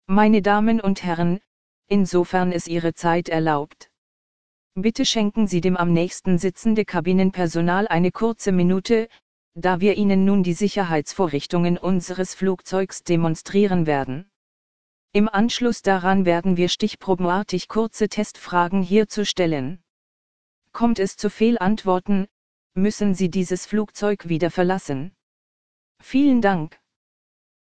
PreSafetyBriefing.ogg